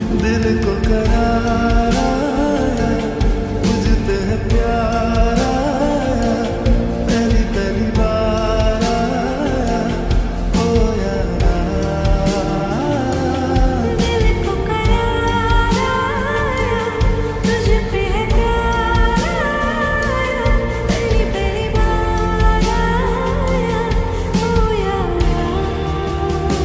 A romantic tone for love moments.